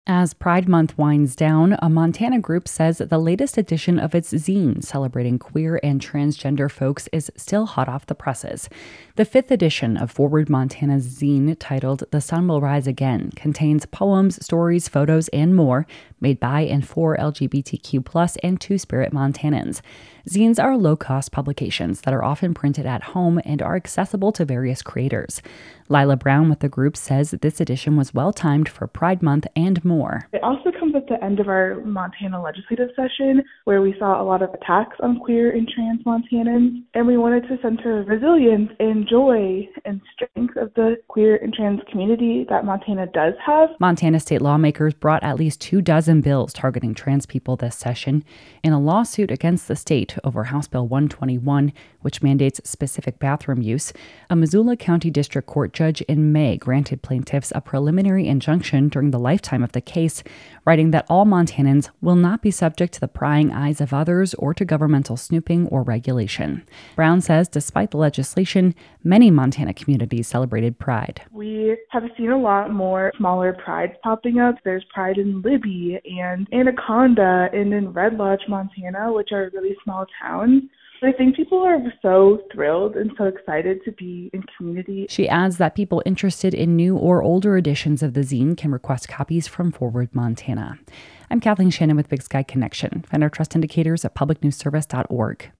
Big Sky Connection - Many towns across Montana celebrated Pride this month, which comes on the heels of a legislative session that featured bills targeting LGBTQ+ people. An advocacy group published the fifth edition of its annual zine[ZEEN], highlighting art by artists from that community.